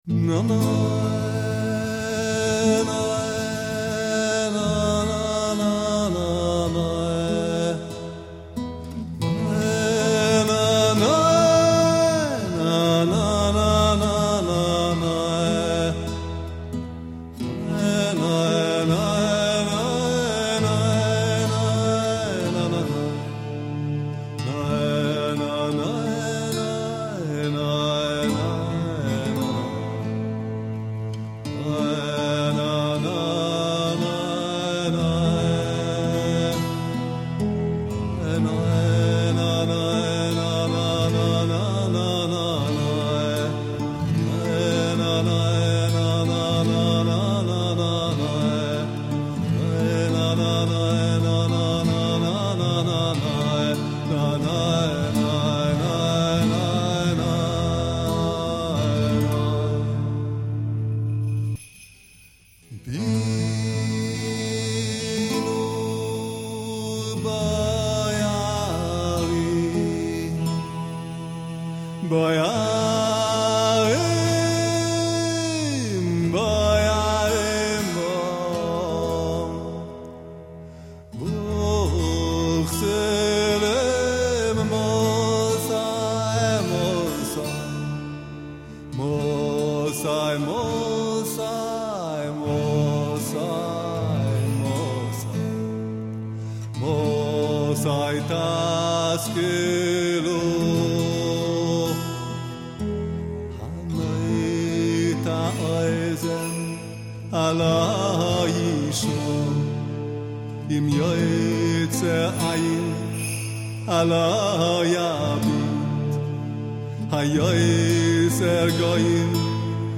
Chassidic Meditation Melodies